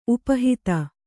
♪ upa hita